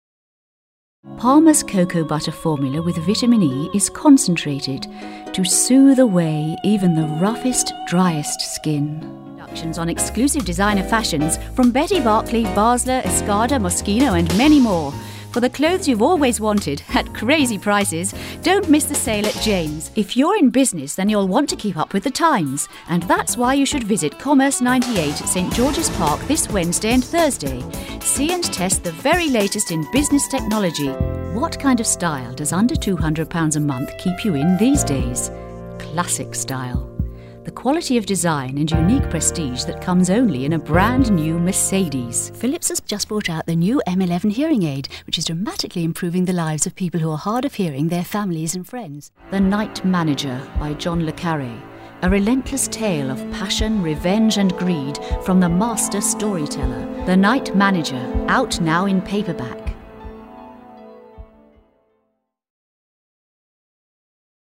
Female
English (British)
Adult (30-50), Older Sound (50+)
Demo Mix